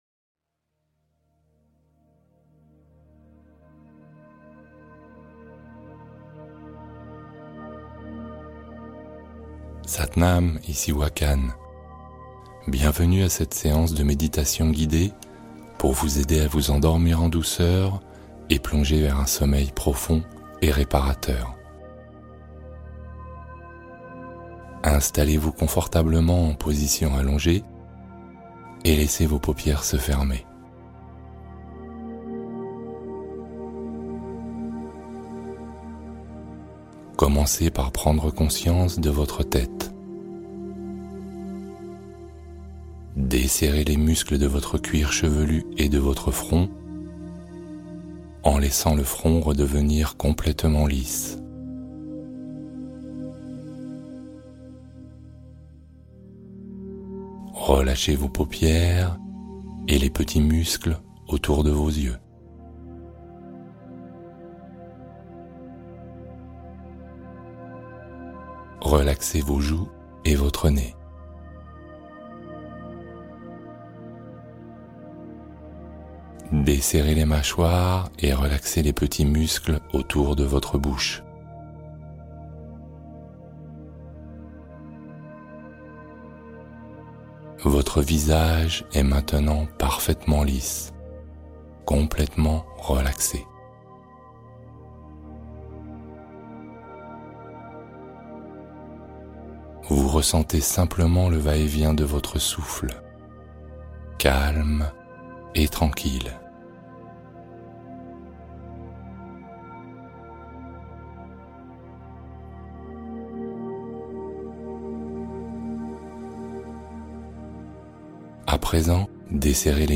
Repos du Bébé : Méditation pour un sommeil serein et régénérateur